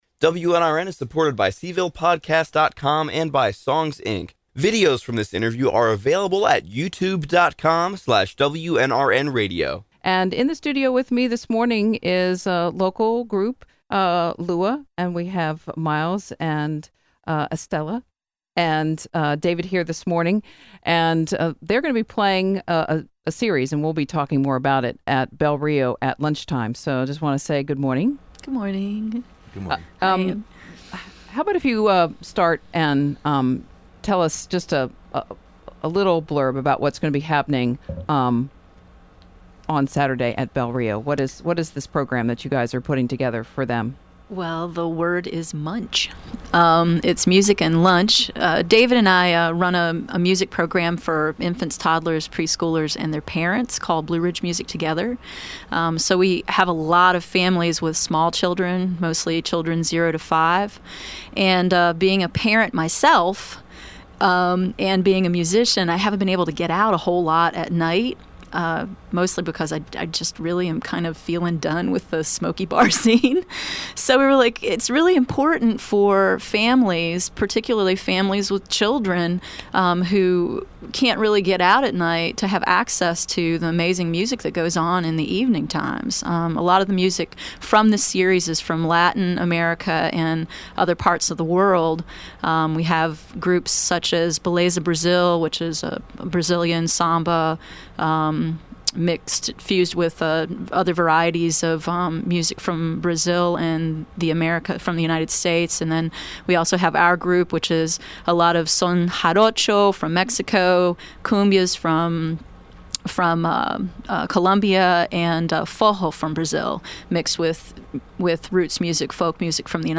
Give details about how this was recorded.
came into our studios